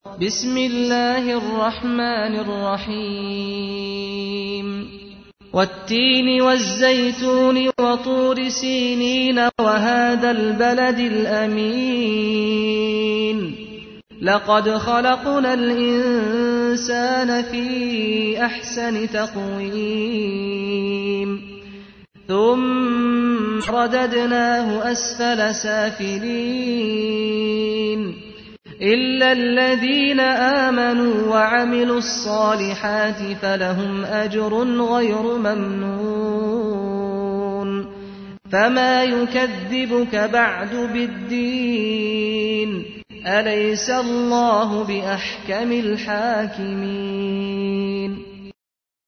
تحميل : 95. سورة التين / القارئ سعد الغامدي / القرآن الكريم / موقع يا حسين